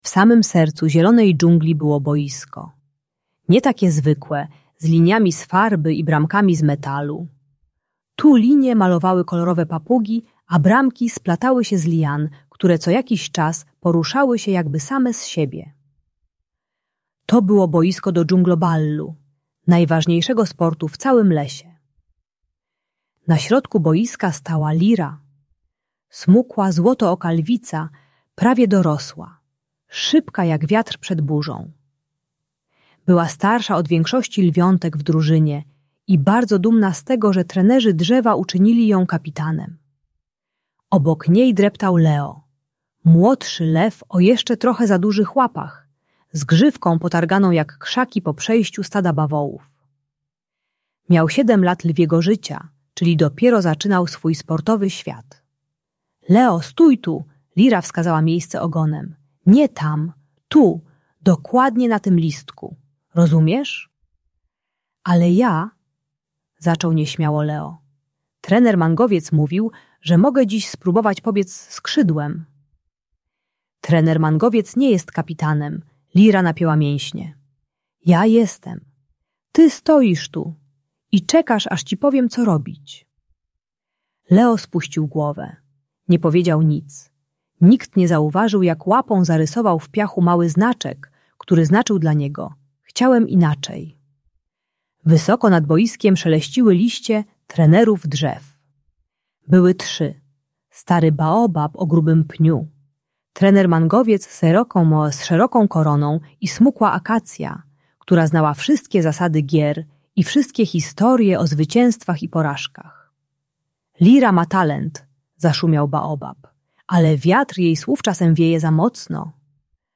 Historia Liry i Leo - Rodzeństwo | Audiobajka